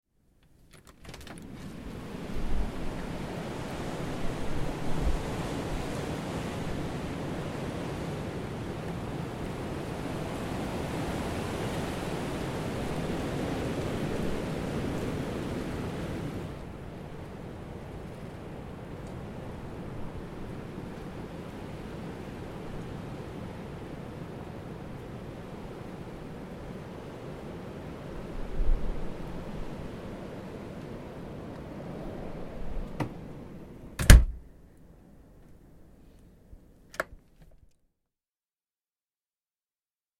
Download Windy sound effect for free.
Windy